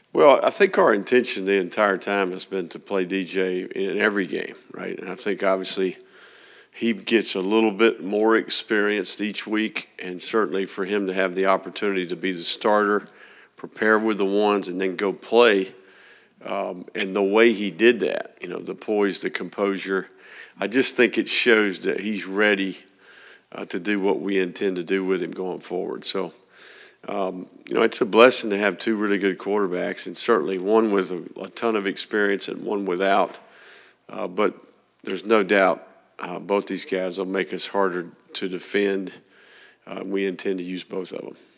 The Gators are fortunate to have two standout quarterbacks and he plans to use both in every game, Florida football coach Billy Napier said at Monday’s news conference.